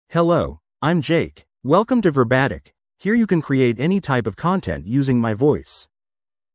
MaleEnglish (United States)
Jake is a male AI voice for English (United States).
Voice sample
Listen to Jake's male English voice.
Jake delivers clear pronunciation with authentic United States English intonation, making your content sound professionally produced.